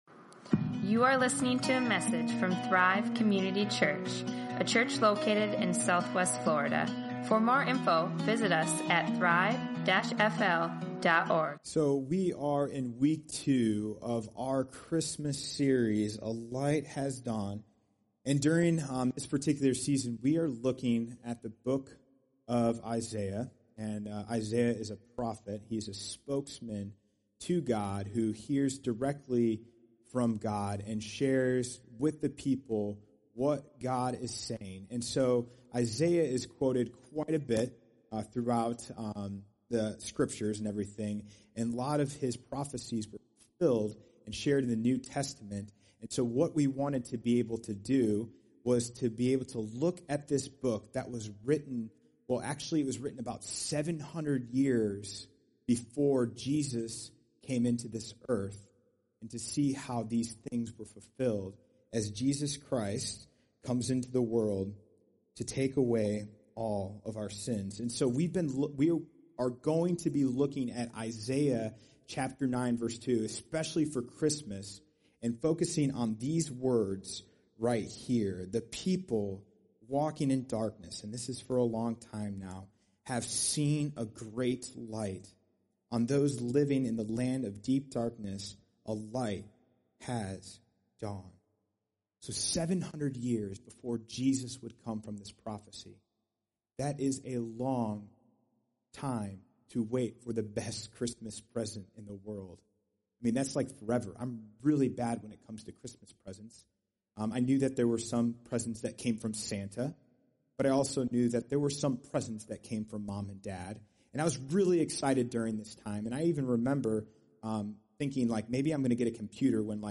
A Light Has Dawned | Sermons | Thrive Community Church